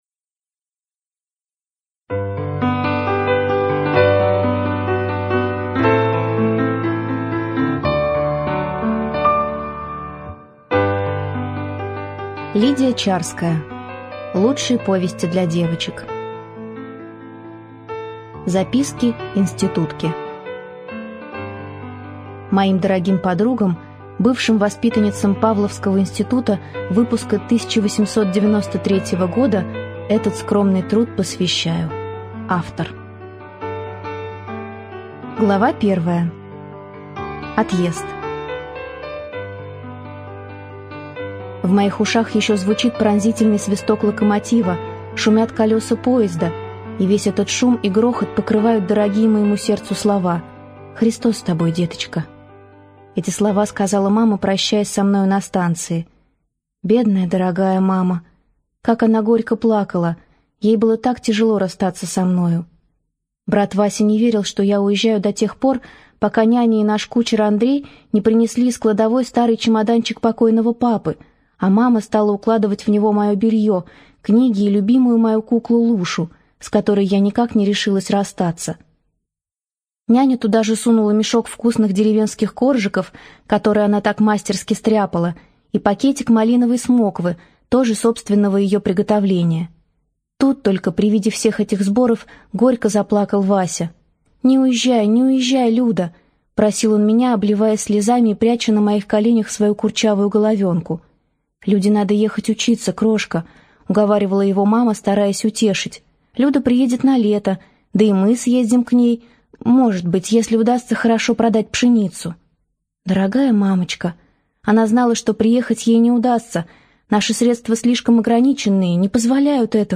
Аудиокнига Записки институтки | Библиотека аудиокниг